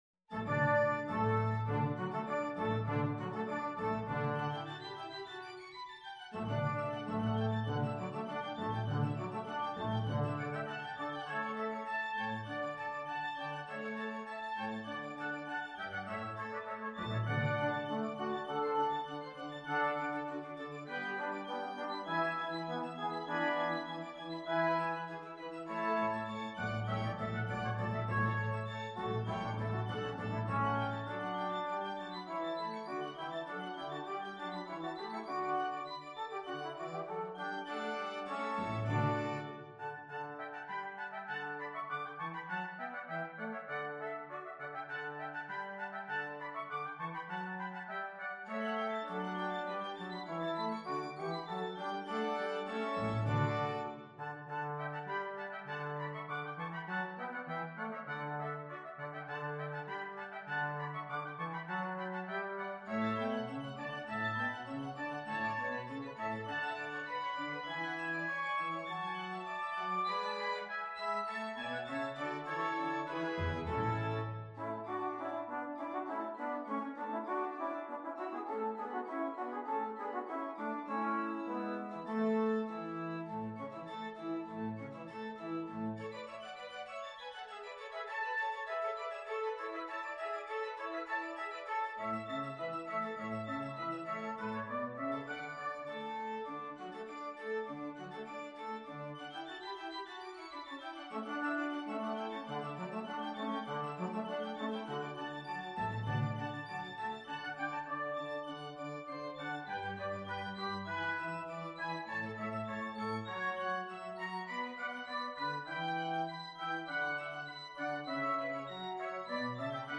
Free Sheet music for Multiple Soloists and Ensemble
Classical (View more Classical Multiple Soloists and Ensemble Music)
concerto-for-2-oboes-in-d-major-op-9-12.mp3